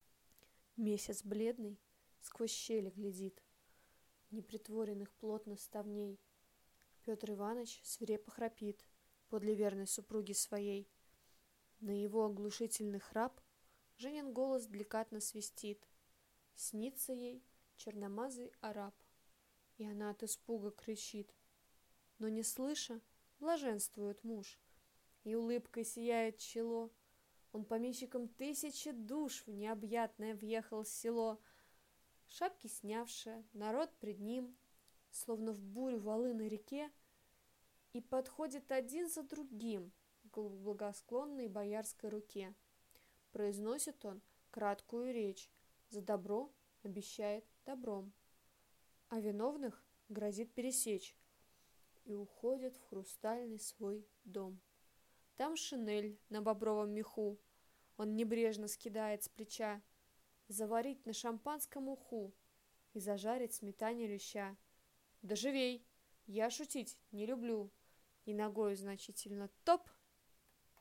Аудиокнига Как опасно предаваться честолюбивым снам | Библиотека аудиокниг